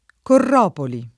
[ korr 0 poli ]